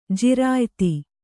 ♪ jirāyti